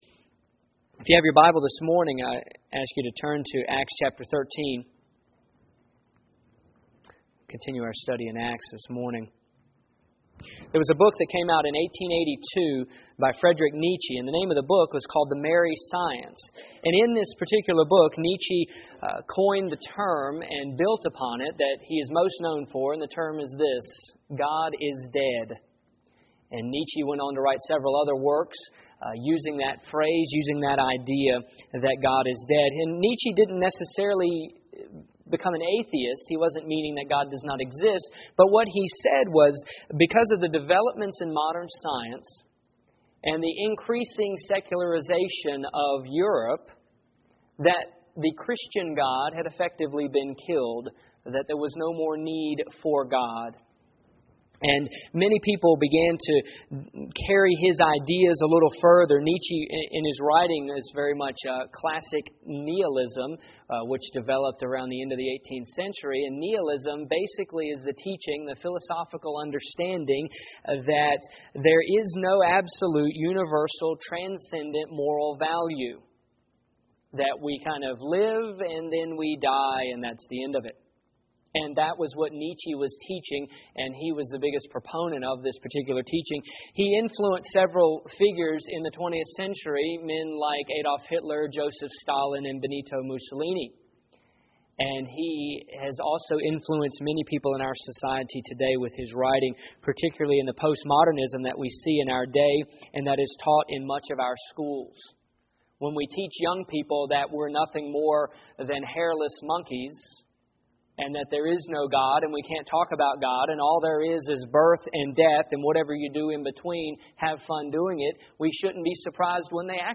Here is my sermon from August 10, 2008